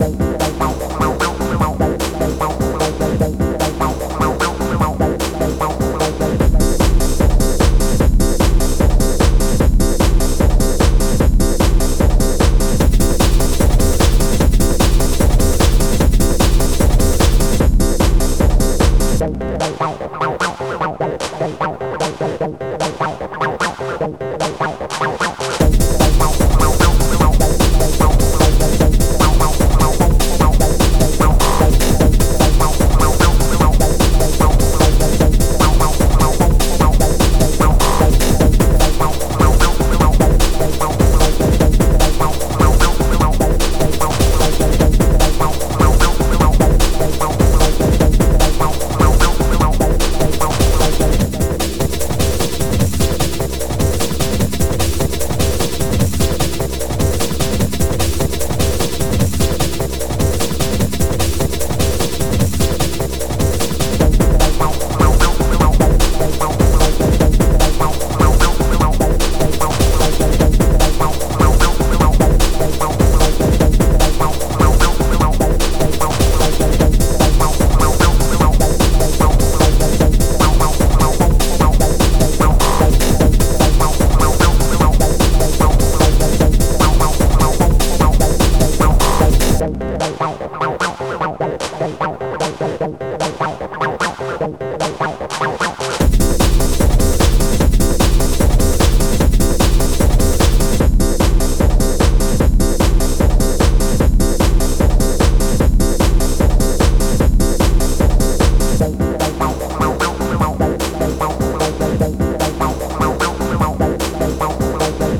Scream Tracker Module